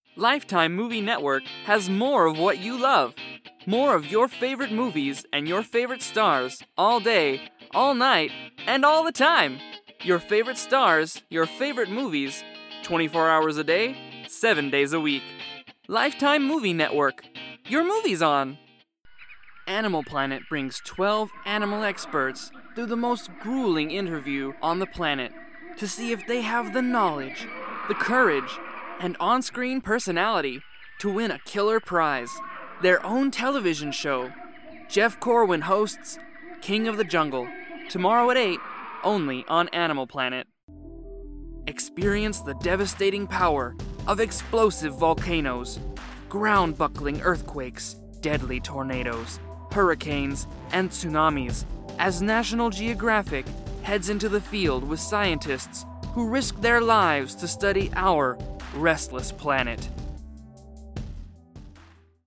外籍男113-电视频道介绍-轻快
激情力度